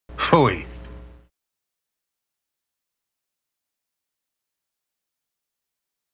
pfui audio  Maury Chaykin's pronunciation #1
Pfui_Chaykin1.mp3